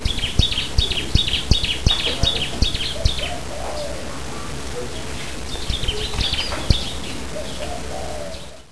Pianeta Gratis - Audio - Natura - Varie
natura_nature01.wav